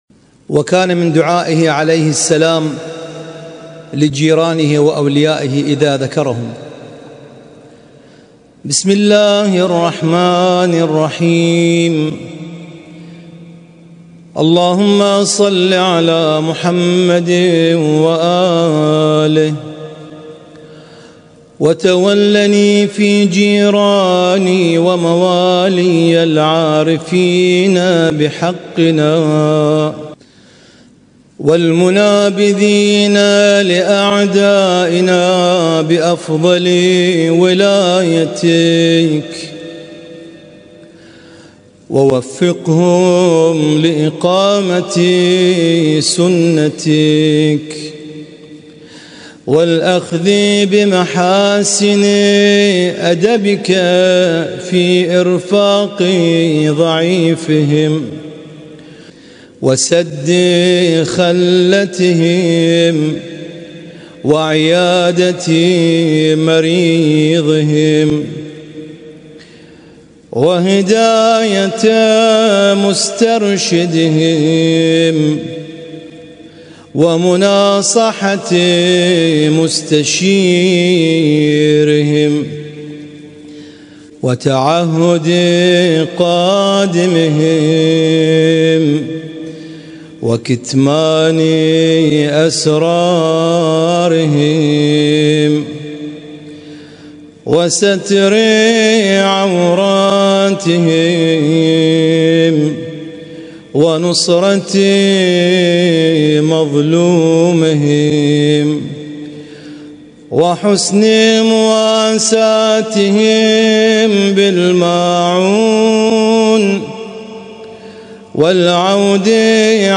القارئ: